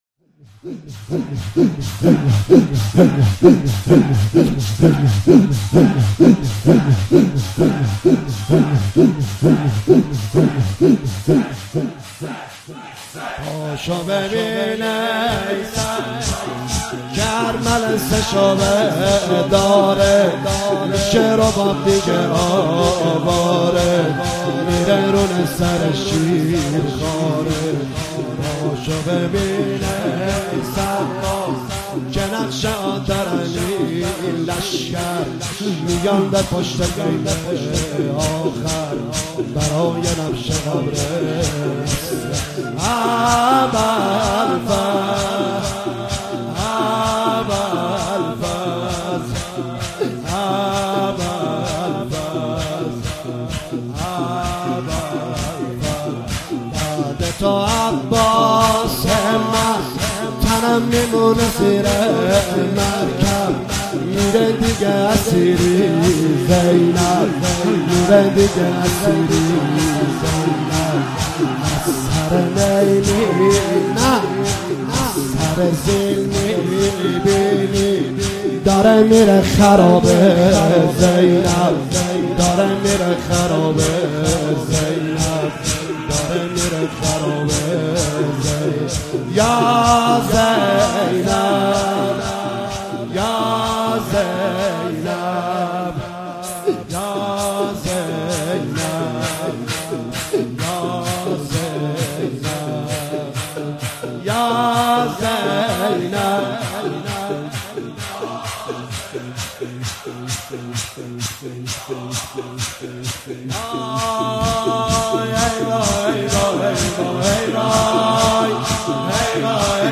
مداحی جدید حاج نریمان پناهی شب چهارم محرم97 هیئت مکتب ‌الحسین(ع)
پا شو ببین ای سقا که حرمله سه شعبه داره - شور